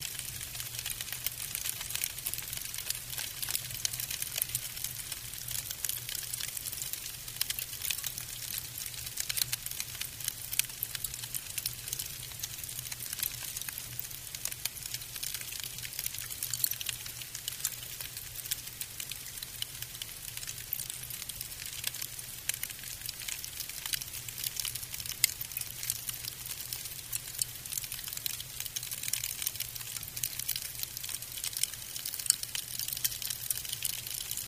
Fire Grill, Small. Fire Burn.